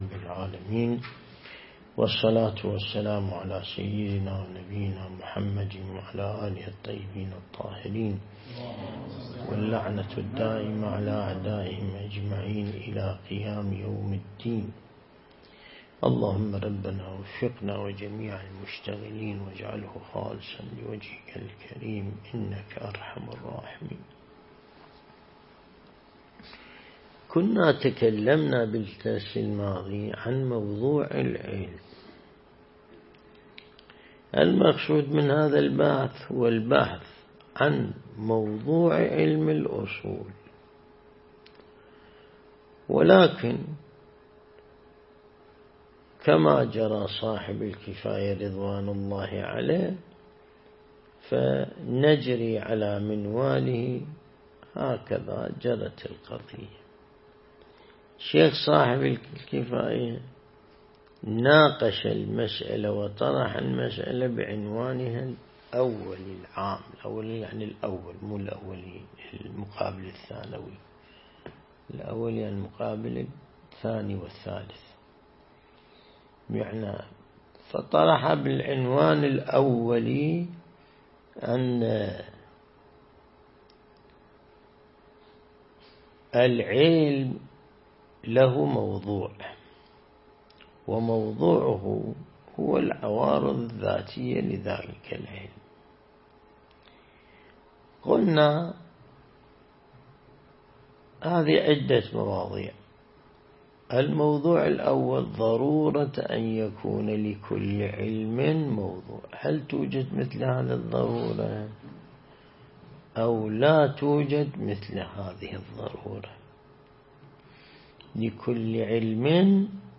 درس البحث الخارج الأصول (30) | الموقع الرسمي لمكتب سماحة آية الله السيد ياسين الموسوي «دام ظله»
النجف الأشرف